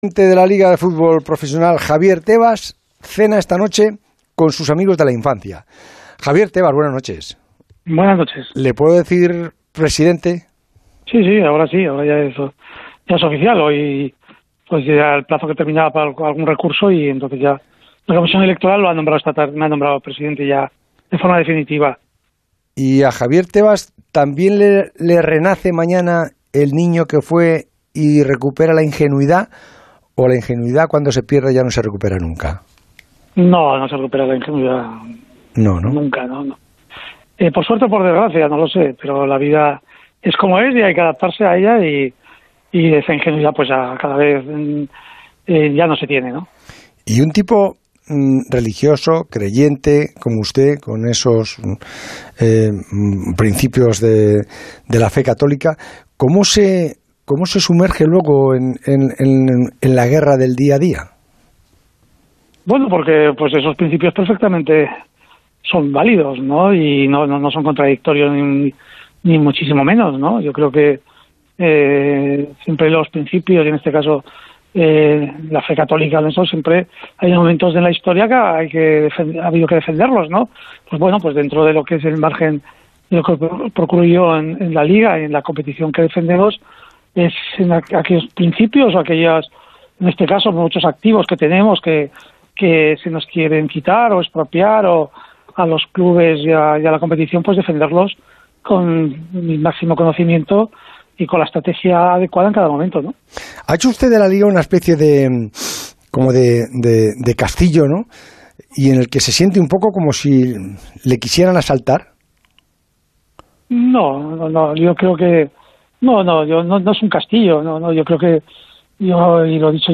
El Transistor, de Onda Cero, entrevistó en la noche del lunes al recién proclamado presidente de LaLiga, Javier Tebas que ha vuelto a ser elegido para un tercer mandato sin oponente en el proceso.